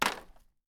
wood.wav